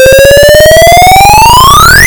Game over
This sound effect is produced by the routine at 35914 during the game over sequence.